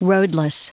Prononciation du mot roadless en anglais (fichier audio)
Prononciation du mot : roadless